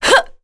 Seria-Vox_Attack2.wav